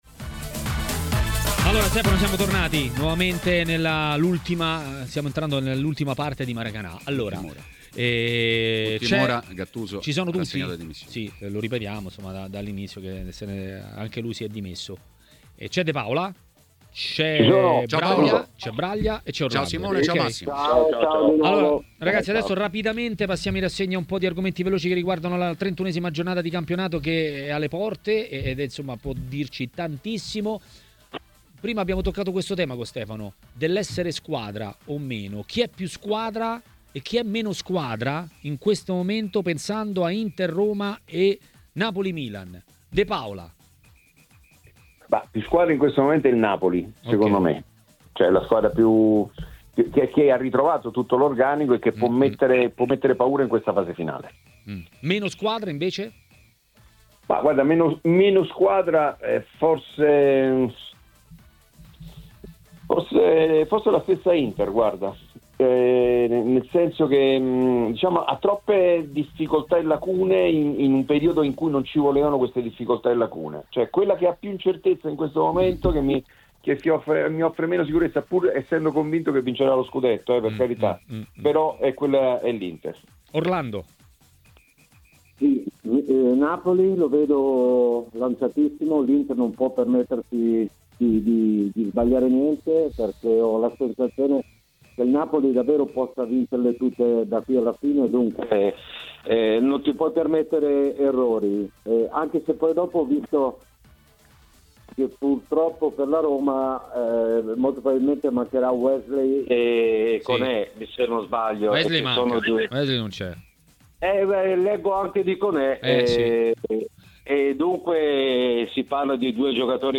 Ascolta l'audio A 'Maracanà', ai microfoni di Tmw Radio , è arrivato il momento dell'ex calciatore Massimo Orlando .